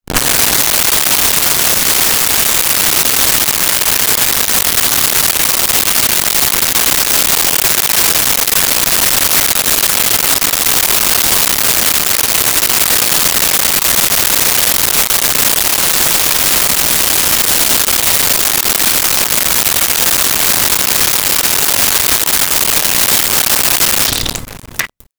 Filling Sink 1
filling-sink-1.wav